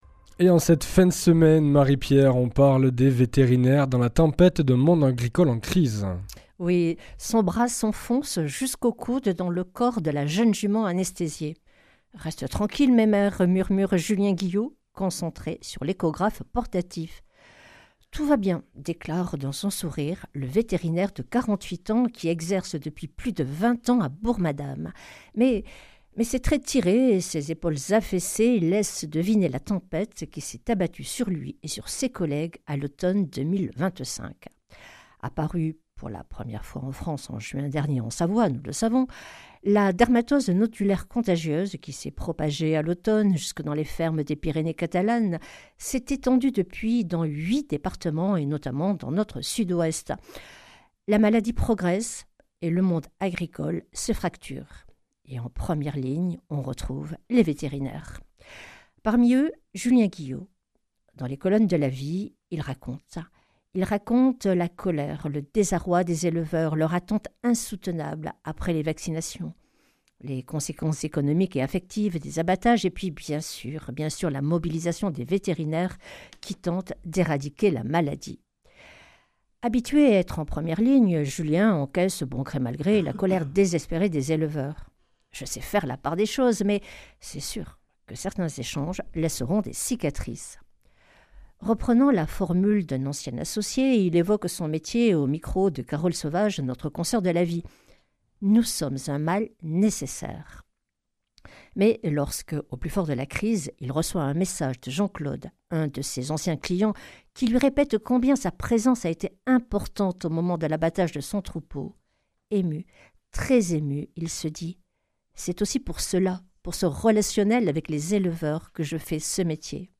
vendredi 19 décembre 2025 La revue de presse chrétienne Durée 5 min
Revue de presse